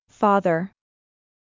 • /ˈfɑːðər/（アメリカ英語：ファーザー
• 「a」の部分は口を大きく開いて「アー」と発音。
• 「th」は舌を軽く前歯に当てて空気を出す/ð/（有声音）
• 「r」は軽く巻くが、イギリス英語ではほぼ聞こえないことも
📌カタカナで書くと「ファーザー」ですが、“r”を強調しすぎないのがポイント。